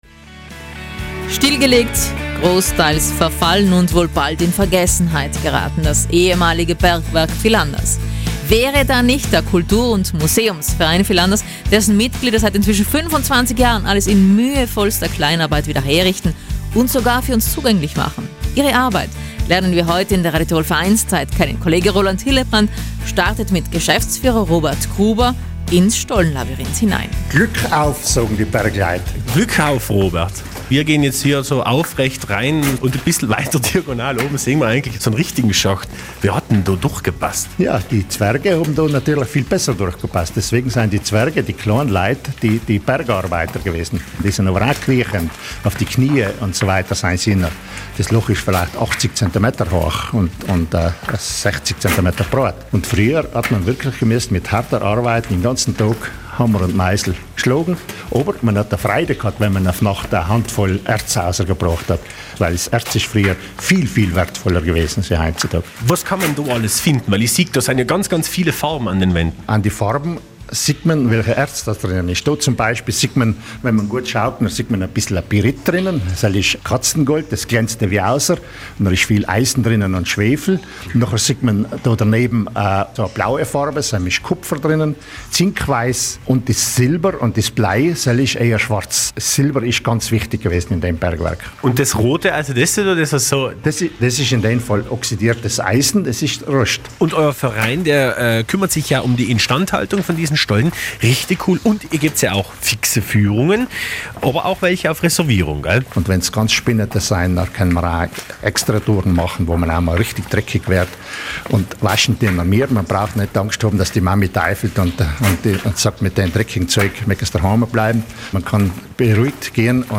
eine Tour durch das Gängelabyrinth im Berg gemacht